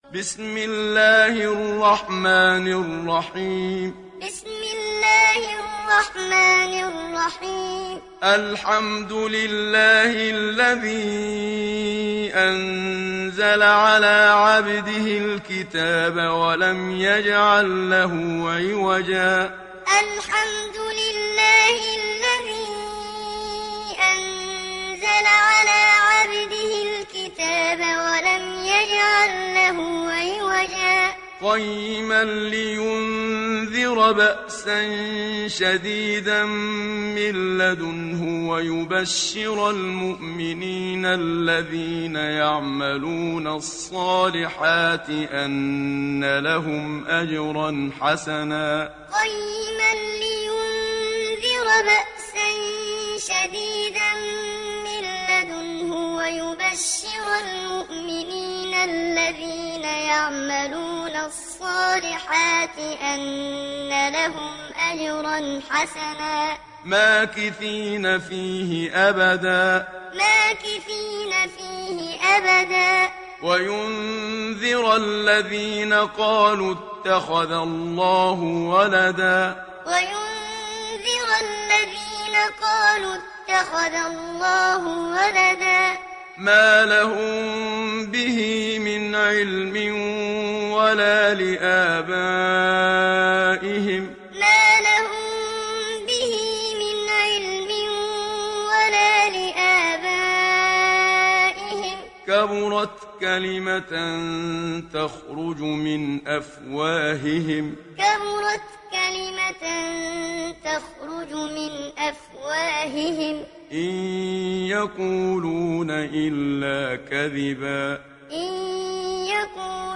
دانلود سوره الكهف محمد صديق المنشاوي معلم